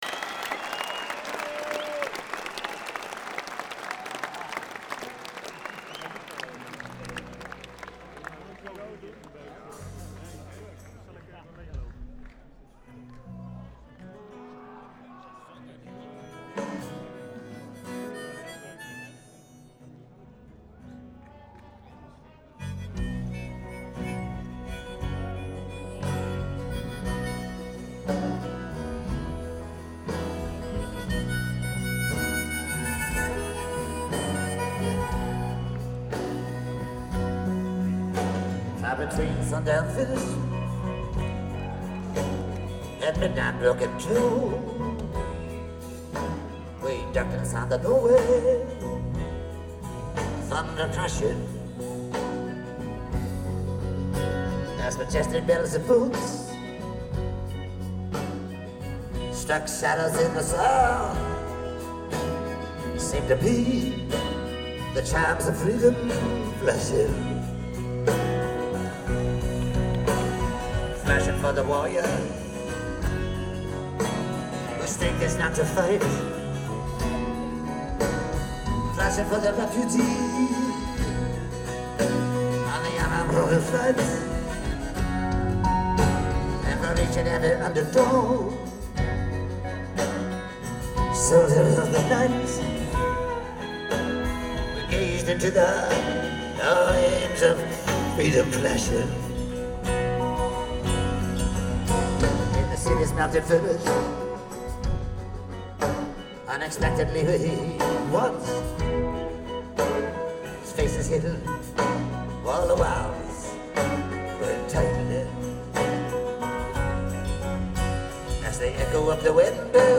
Heineken Music Hall - Amsterdam, Netherlands